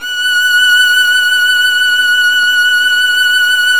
Index of /90_sSampleCDs/Roland L-CD702/VOL-1/STR_Violin 1-3vb/STR_Vln2 % + dyn
STR  VL F#7.wav